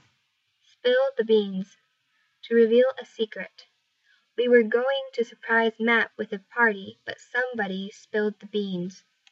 英語ネイティブによる発音は下記のリンクをクリックしてください。
Spillthebeans.mp3